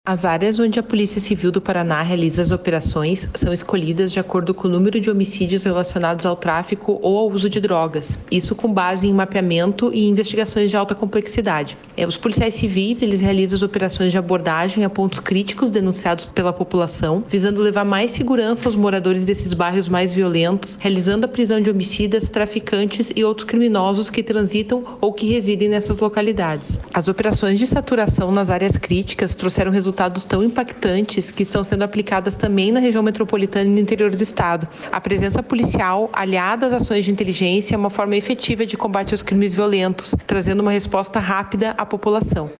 Sonora da delegada-chefe da Divisão de Homicídios e Proteção à Pessoa em Curitiba, Camila Cecconello, sobre a redução de 27% nos homicídios em Curitiba em 2023